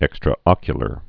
(ĕkstrə-ŏkyə-lər)